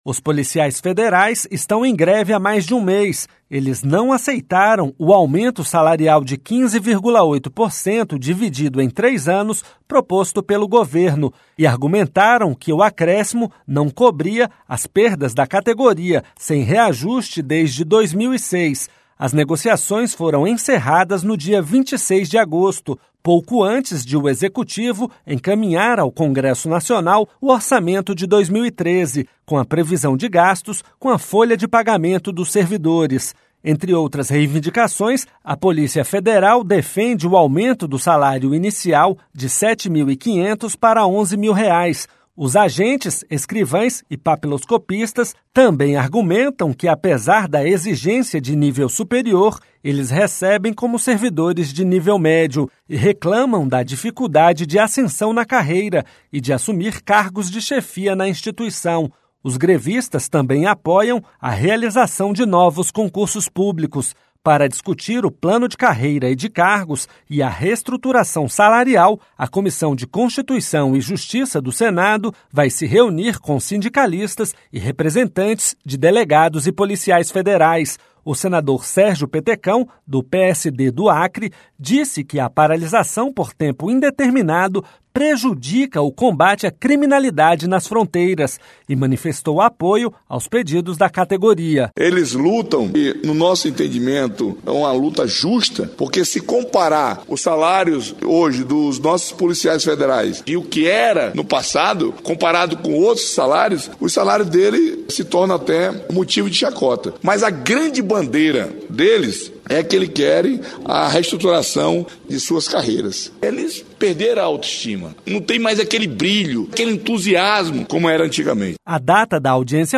Senador Sérgio Petecão